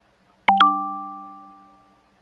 notification.af039bbf.mp3